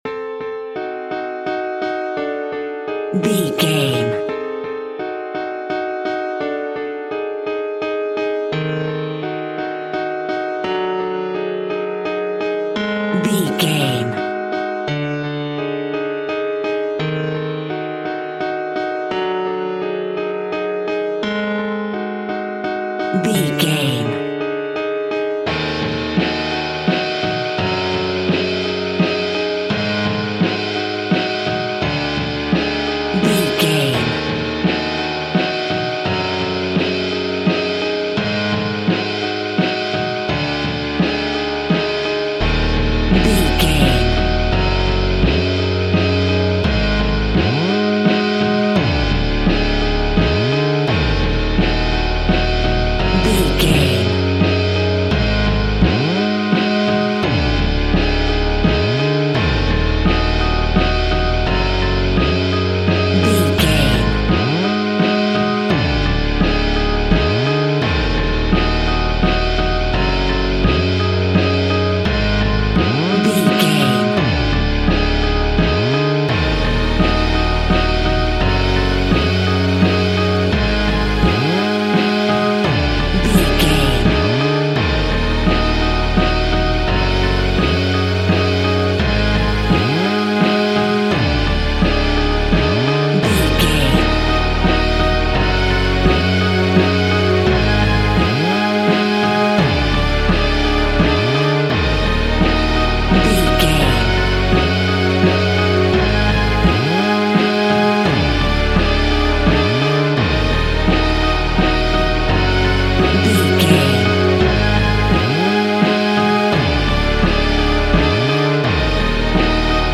In-crescendo
Aeolian/Minor
tension
ominous
dark
suspense
haunting
eerie
strings
synth
ambience
pads